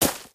sounds / material / human / step / t_gravel1.ogg
t_gravel1.ogg